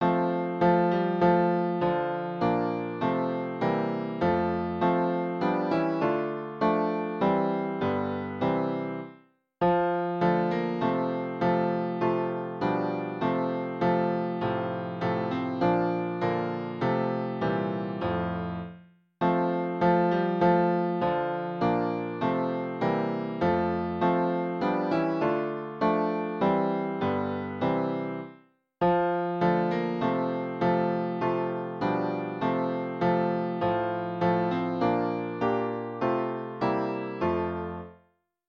067-Til fjalla (án texta)